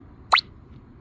落地音效.mp3